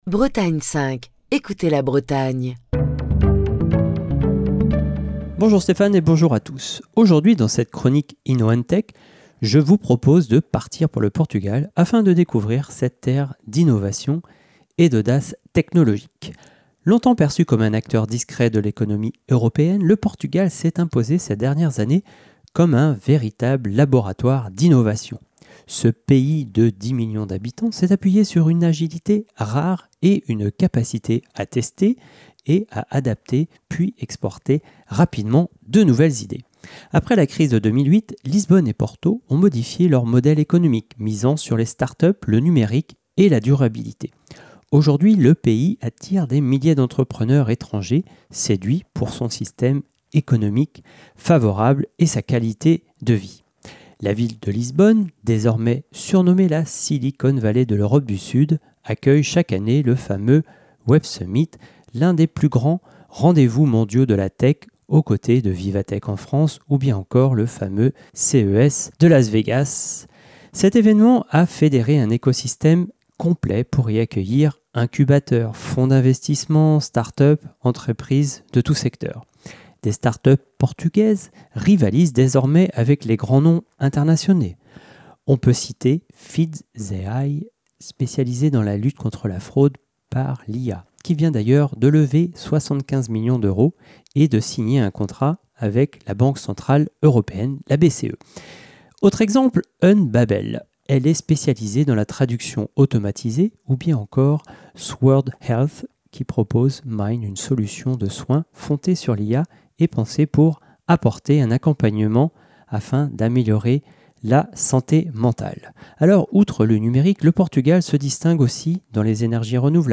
Chronique du 20 octobre 2025.